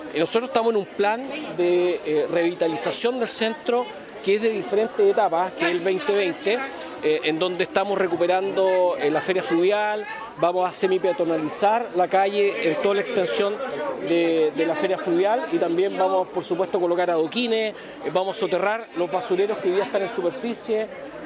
Inauguración Feria Fluvial | Archivo RioenLinea
En la instancia, el Alcalde Sabat, aseguró que se encuentran trabajando en un plan para revitalizar el centro de la ciudad y donde una de las etapas de este proyecto, era justamente remodelar la Feria Fluvial, que pretenden conectar además, con la remodelación del primer tramo de la costanera.